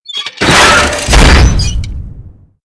CHQ_VP_big_jump_stomp.ogg